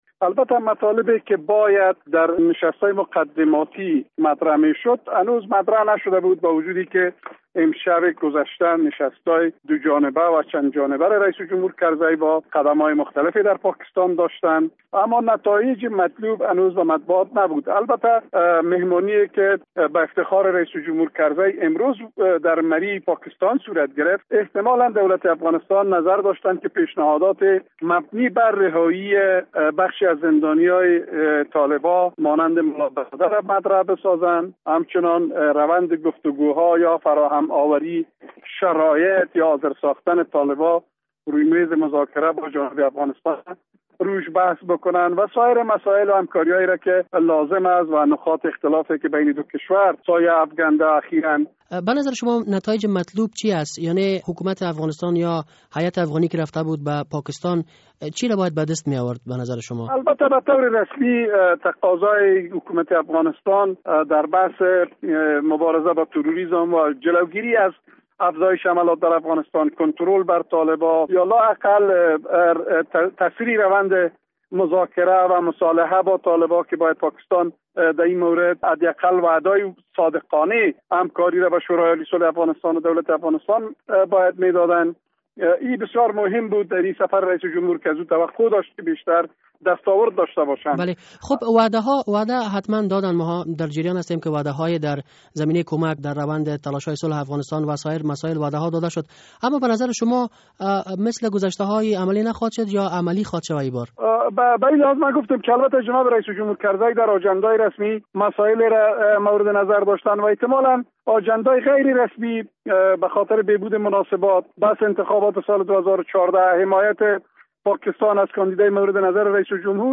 مصاحبه در مورد نتایج سفر حامد کرزی به پاکستان